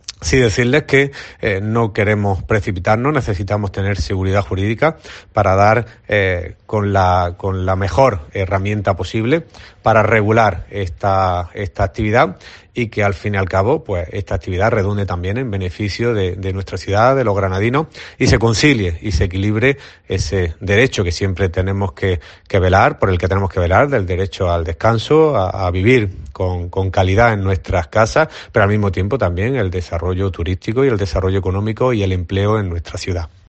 Jorge Saavedra, teniente de alcalde del Ayuntamiento